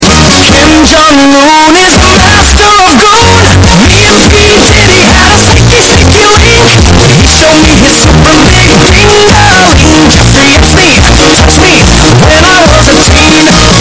Kim Jong Un Distorted Sound Button - Free Download & Play